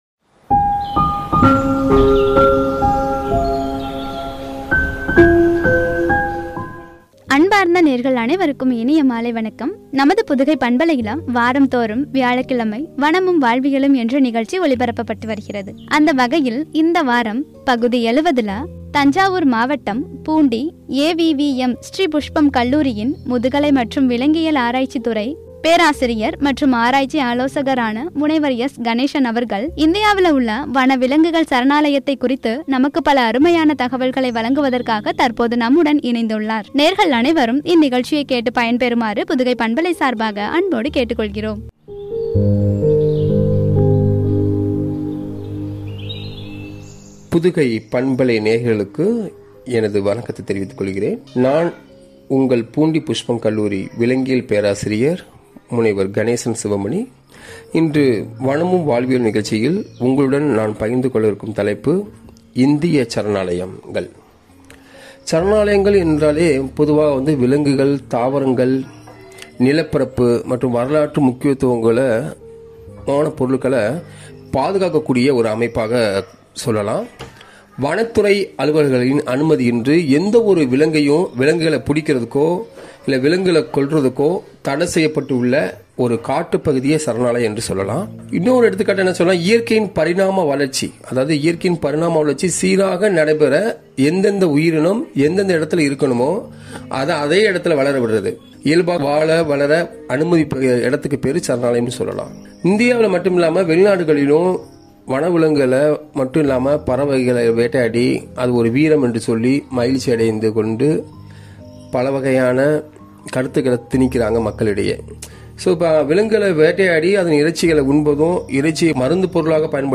“வனவிலங்குகள் சரணாலயங்கள்” என்ற தலைப்பில் வழங்கிய உரை.